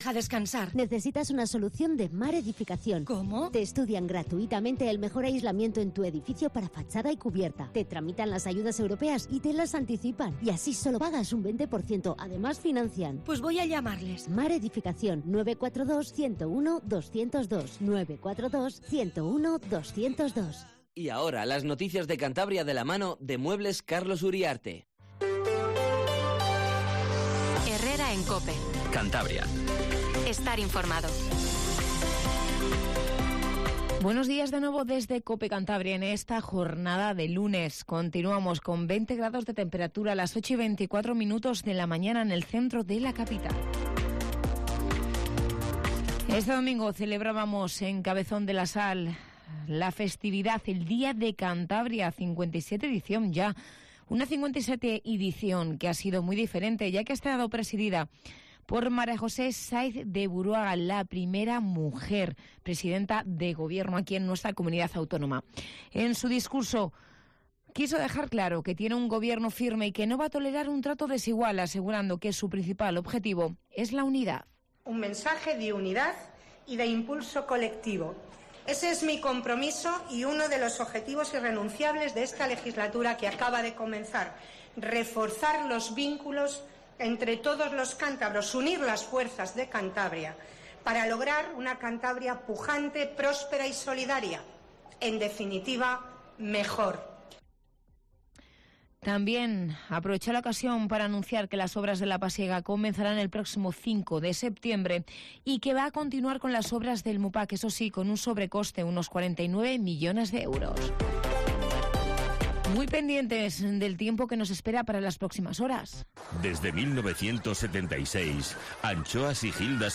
Informativo Matinal Cope 08:20